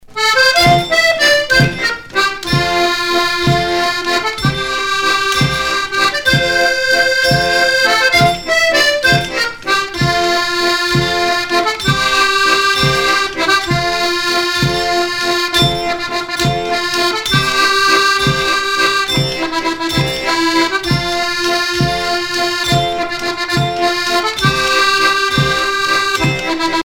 danse : valse
Chanteurs et musiciens de villages en Morvan
Pièce musicale éditée